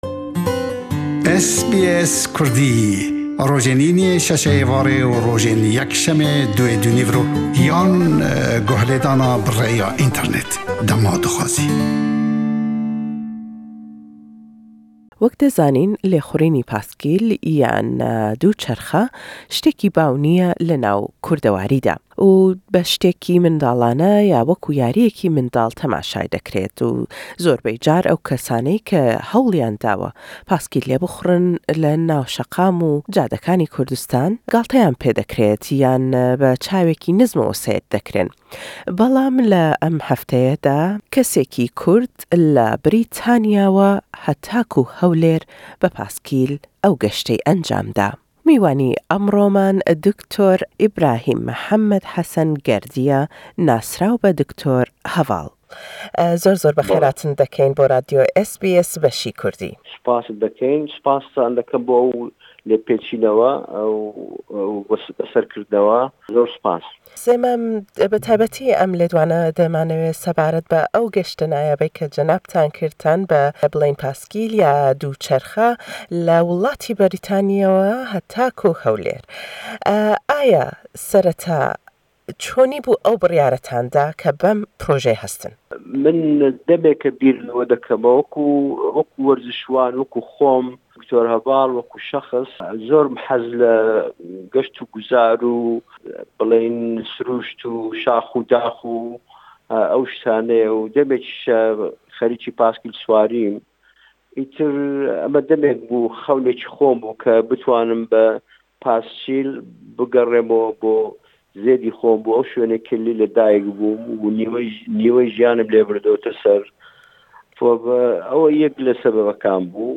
Le em lêdwane da ême pirsîyarî lê dekeyn sebaret be ew azmûne nayabe, ke zîyatir le dû mangî xayan.